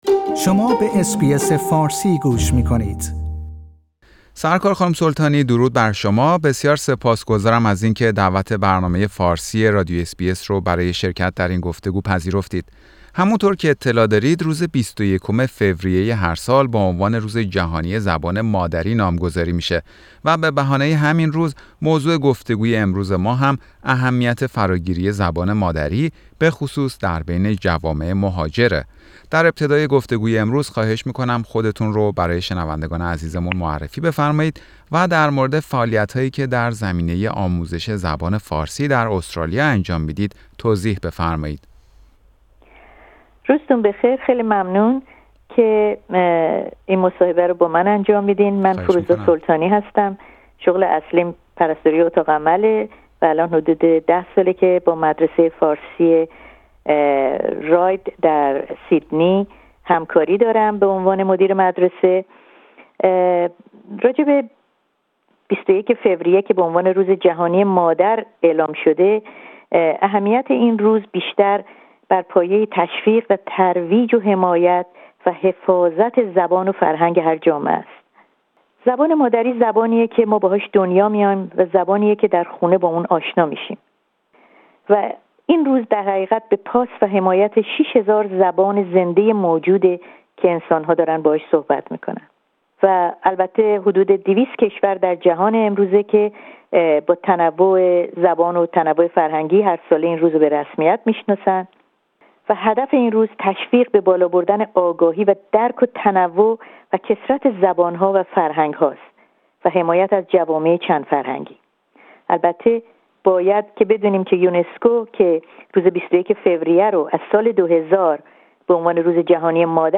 روز بین المللی زبان مادری و گفتگو با مدیر یک مدرسه فارسی در استرالیا